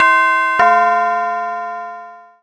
Modern Doorbell
Bell Ding Door Door-bell sound effect free sound royalty free Sound Effects